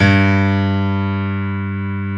G1-PNO93L -L.wav